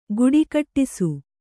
♪ guḍikaṭṭisu